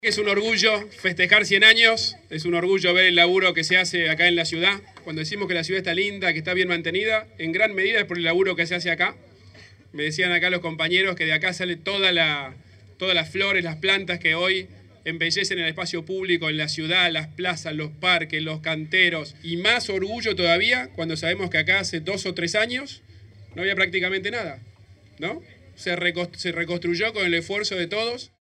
El jefe de Gobierno porteño, Horacio Rodríguez Larreta, participó del festejo por el aniversario número 100 del Vivero de la Ciudad, y destacó que “de acá salen todas las flores y plantas que hoy embellecen el espacio público, las plazas y los parques”.